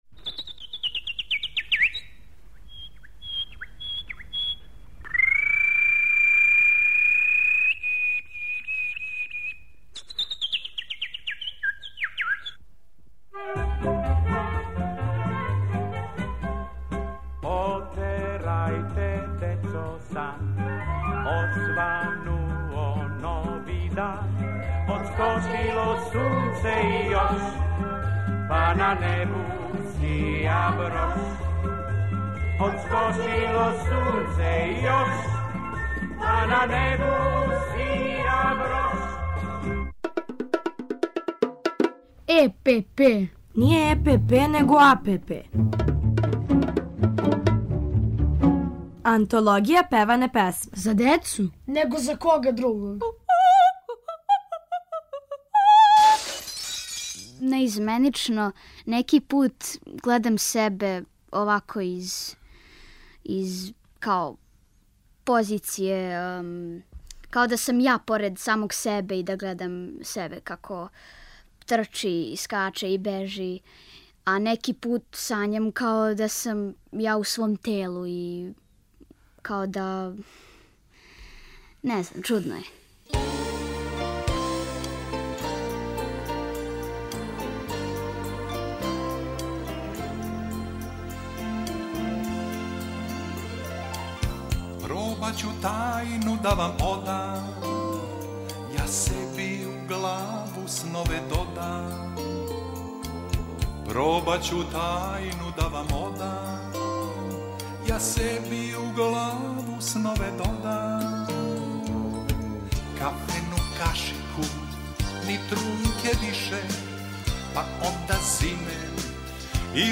У серијалу Антологија певане песме певамо и размишљамо о сновима, уз хор Колибри и Дечију драмску групу Радио Београда.